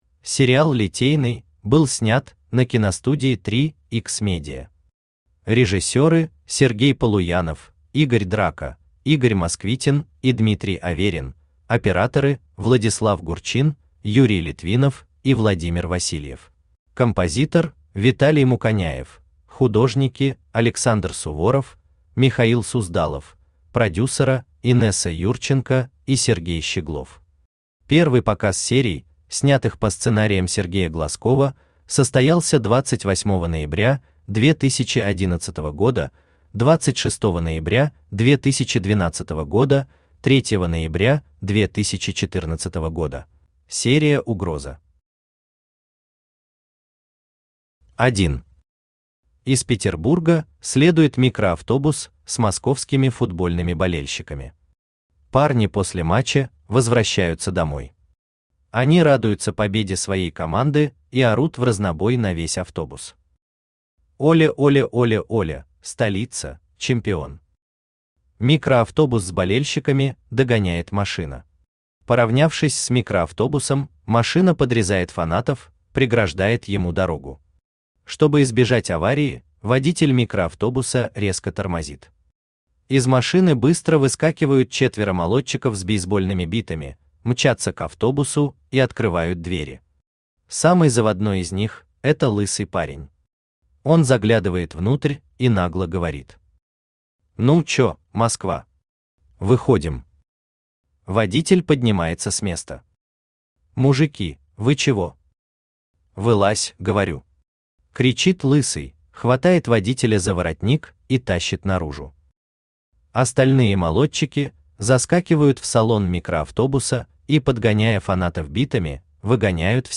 Аудиокнига Угроза, Смертник | Библиотека аудиокниг
Aудиокнига Угроза, Смертник Автор Сергей Алексеевич Глазков Читает аудиокнигу Авточтец ЛитРес.